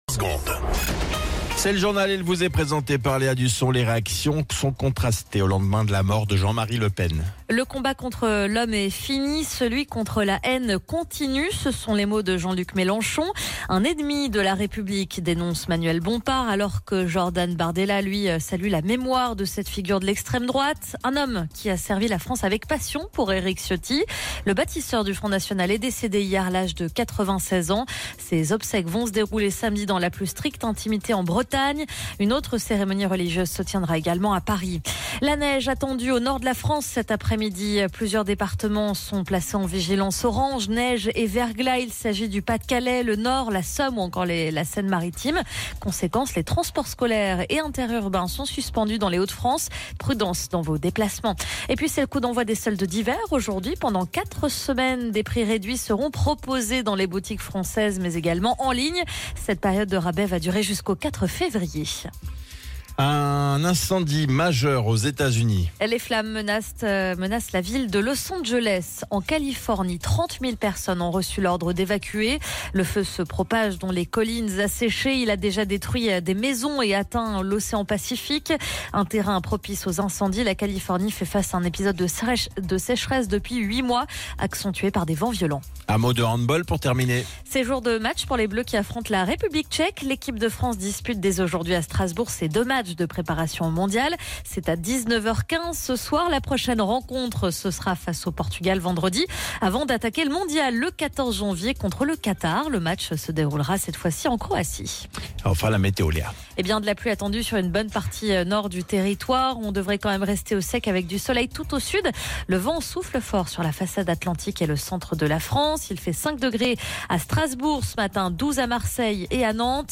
Flash Info National 08 Janvier 2025 Du 08/01/2025 à 07h10 .